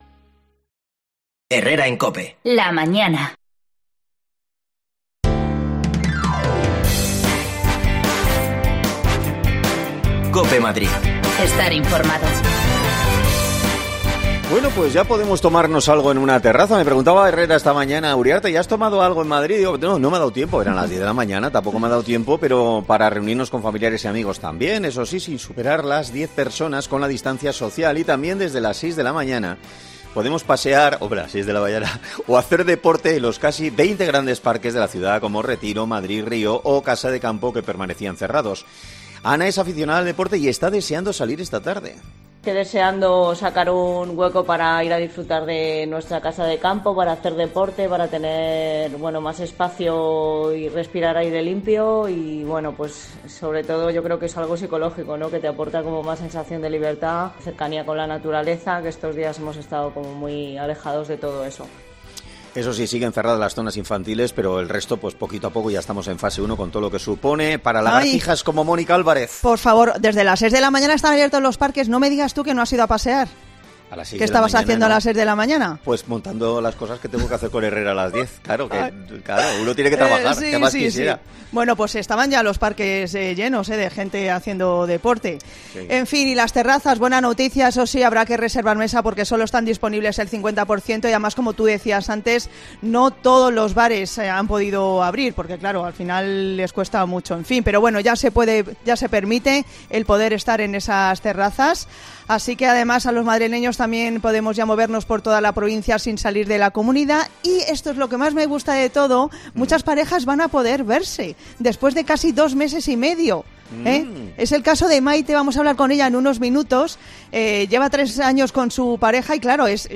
Escucha ya las desconexiones locales de Madrid de Herrera en COPE en Madrid y Mediodía COPE en Madrid .
Las desconexiones locales de Madrid son espacios de 10 minutos de duración que se emiten en COPE , de lunes a viernes.